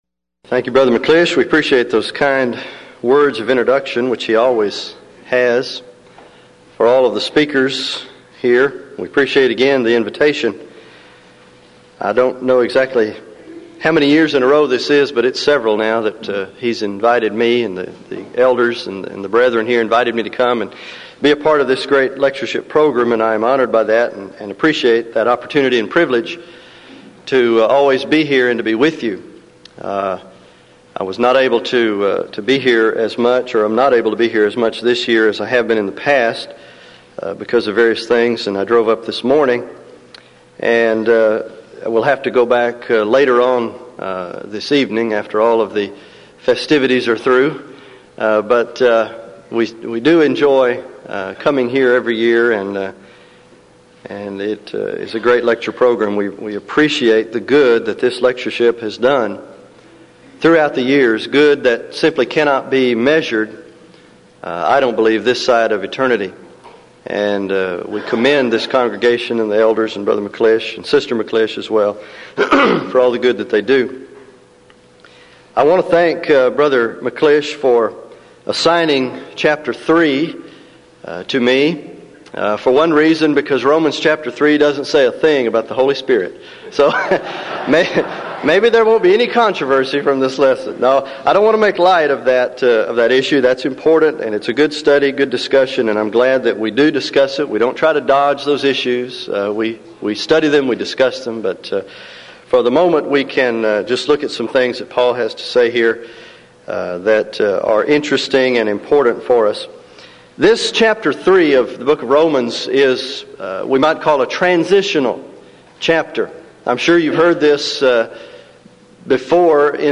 Event: 1996 Denton Lectures Theme/Title: Studies In The Book Of Romans